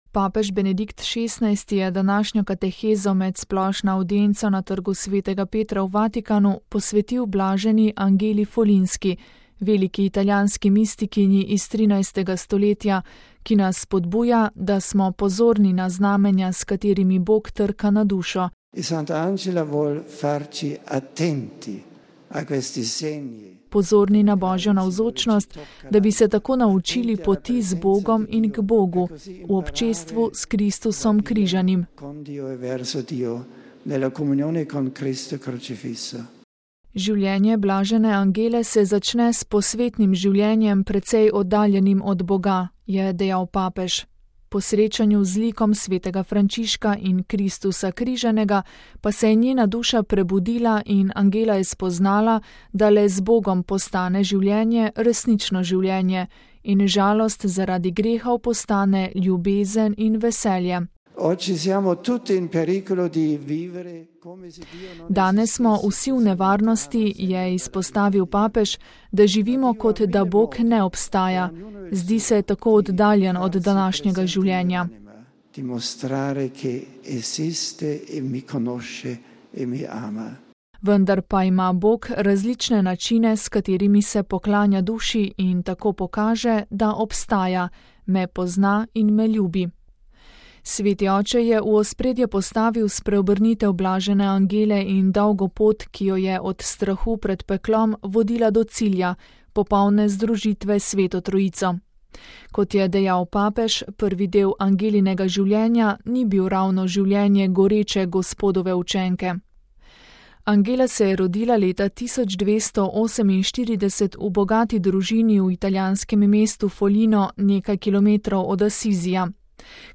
VATIKAN (sreda, 13. oktober 2010, RV) – Papež Benedikt XVI. je današnjo katehezo med splošno avdienco, ki je potekala na trgu sv. Petra v Vatikanu, posvetil bl. Angeli Folinjski, veliki italijanski mistikinji iz 13. stoletja, ki nas spodbuja, da »smo pozorni na znamenja, s katerimi Bog trka na dušo, pozorni na Božjo navzočnost, da bi se tako naučili poti z Bogom in k Bogu, v občestvu s Kristusom Križanim.«